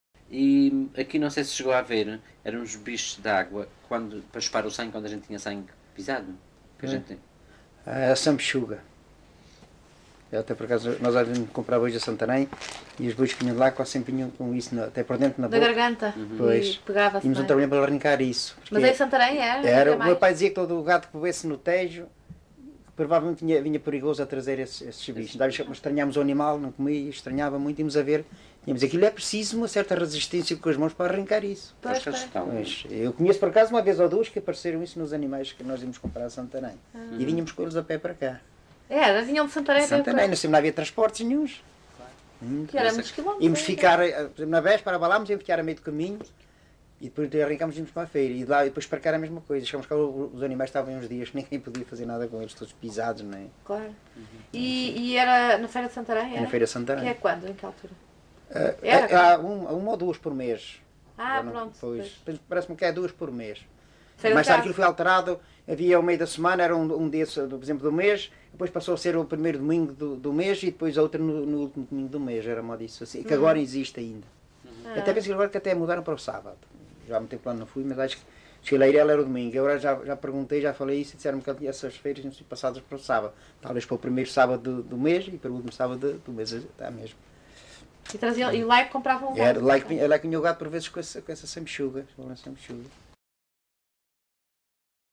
LocalidadeMoita do Martinho (Batalha, Leiria)